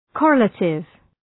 Shkrimi fonetik {kə’relətıv}
correlative.mp3